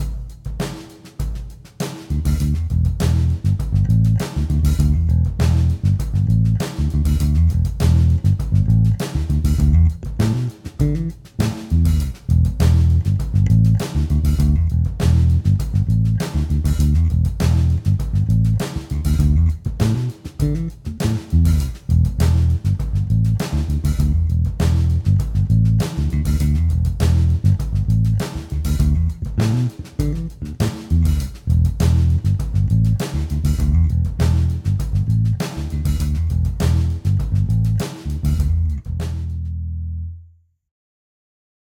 Edit: nahráváno přes VSTčko Ampeg B15R
AV2 Precl, struny rok staré. 3. Suhr Classic-J, struny víceméně nové, ale ocel. 4.
3 mi přijde jako, že jediná čumí, byť mému vkusu dolů - ale to bude zřejmě tou ocelí, na mne moc boomy.